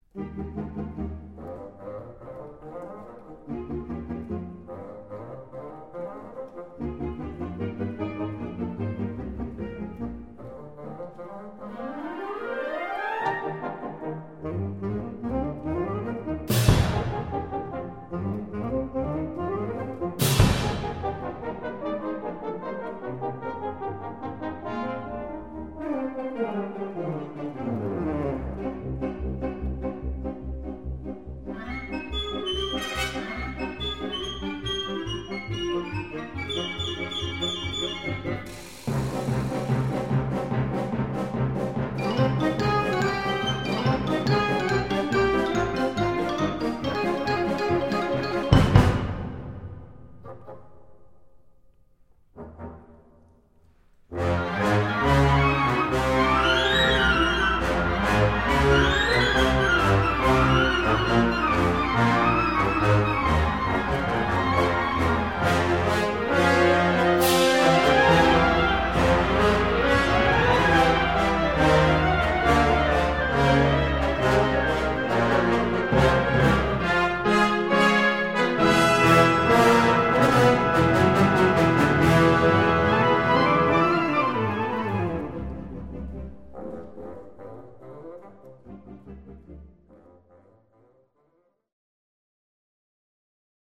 Kategorie Blasorchester/HaFaBra
Unterkategorie Zeitgenössische Originalmusik (20./21.Jhdt)
Besetzung Ha (Blasorchester)